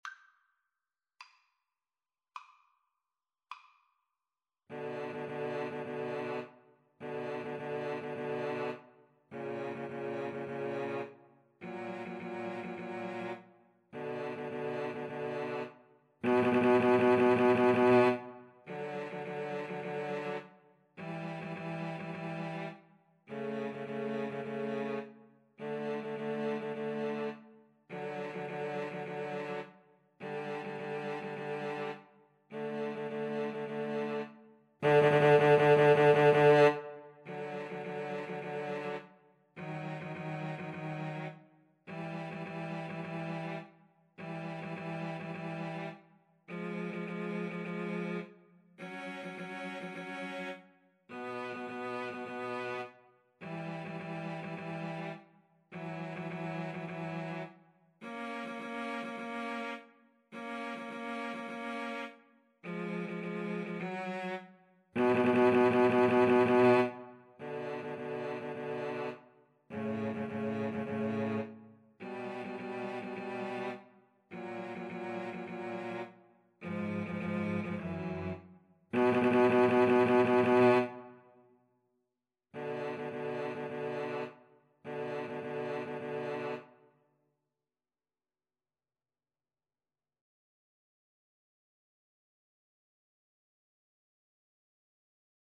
Free Sheet music for Cello Trio
B minor (Sounding Pitch) (View more B minor Music for Cello Trio )
Adagio = c. 52
Classical (View more Classical Cello Trio Music)